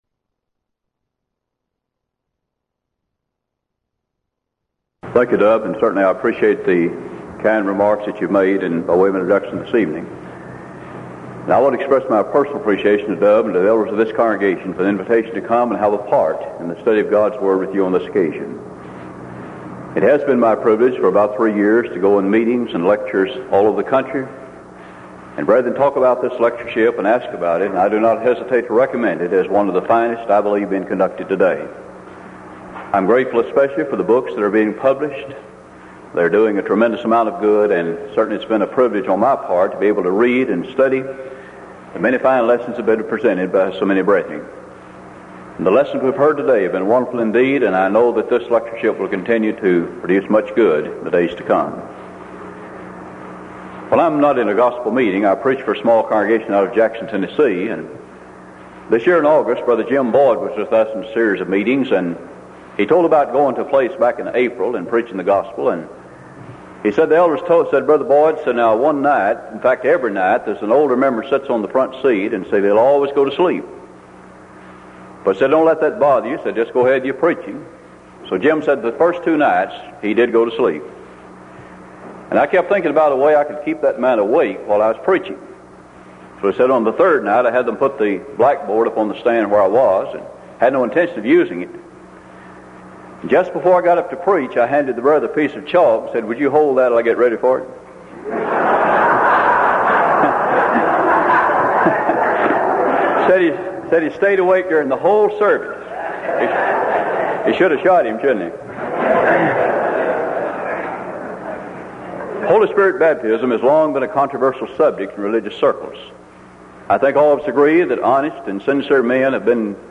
Event: 1985 Denton Lectures Theme/Title: Studies in Acts